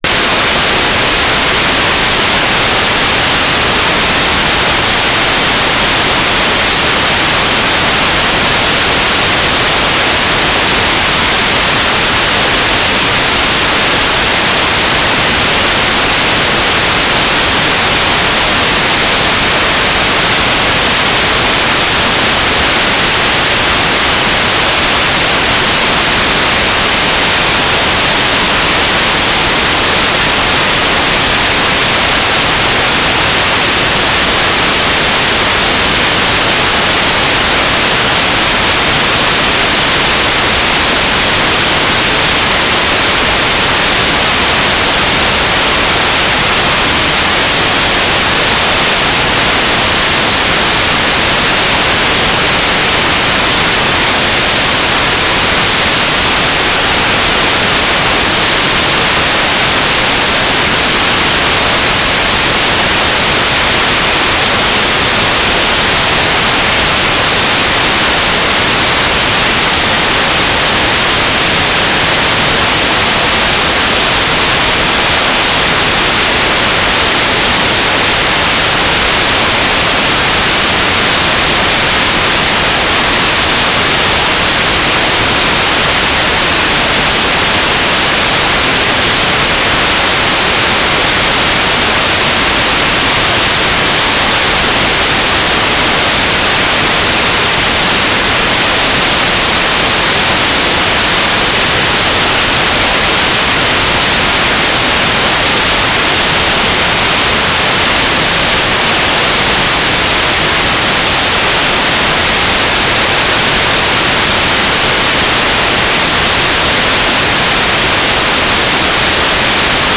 "transmitter_description": "Mode V/U - FM Transceiver",